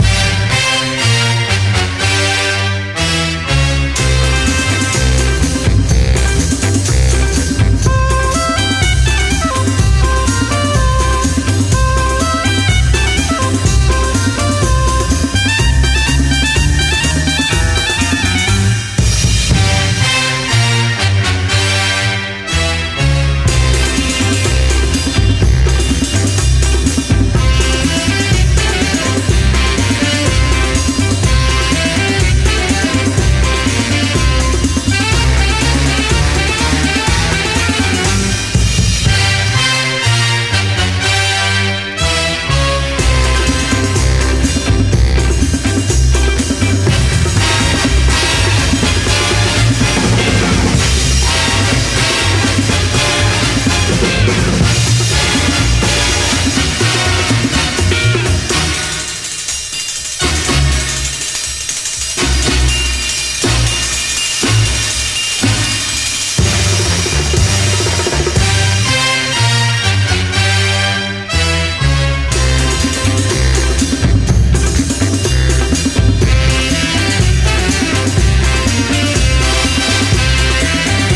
JAZZ / DANCEFLOOR / JAZZ FUNK
打ちまくりのビートにも悶絶、汗だくで踊りたいキラー・ファンク・チューンです！